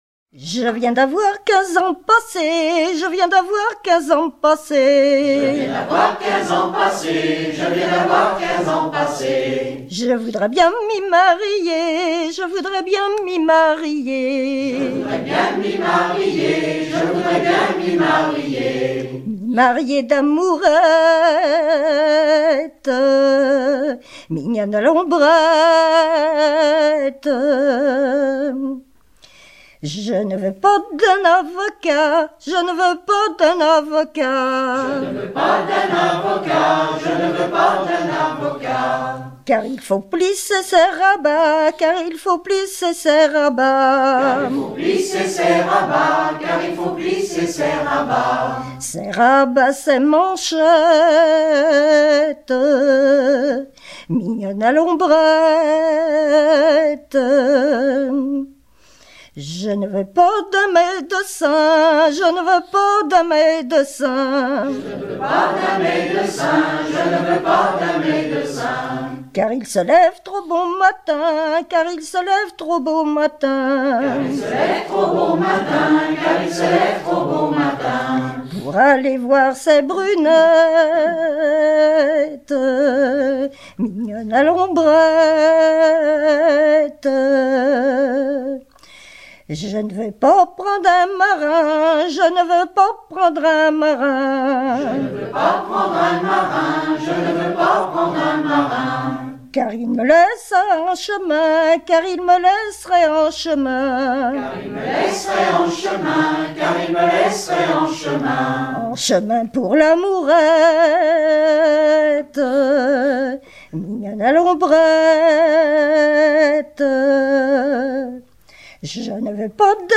Genre énumérative